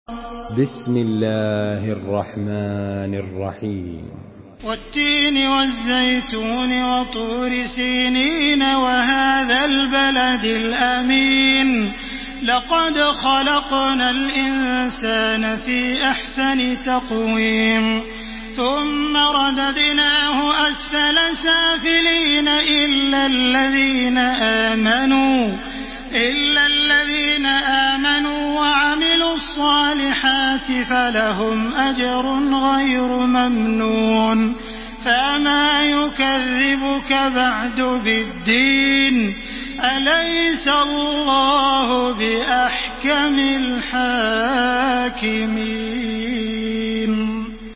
Surah At-Tin MP3 by Makkah Taraweeh 1433 in Hafs An Asim narration.
Murattal Hafs An Asim